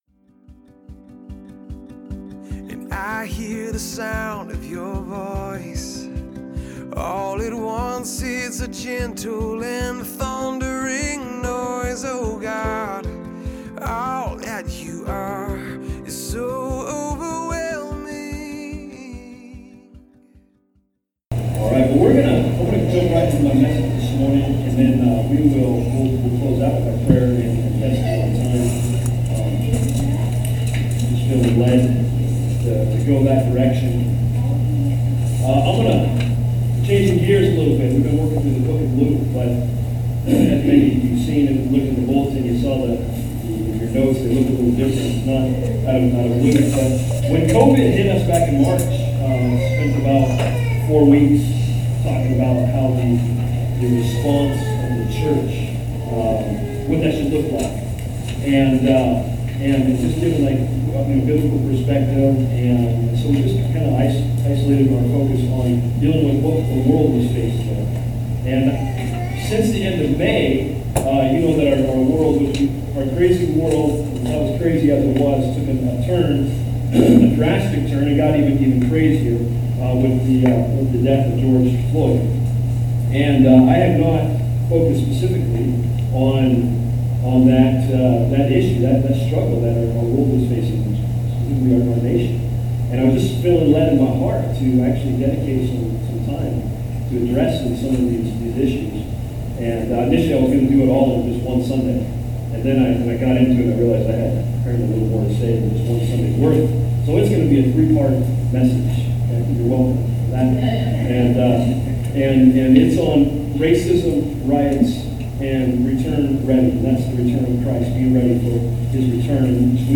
We had technicle issues and do apologize for the low quality recording of this message.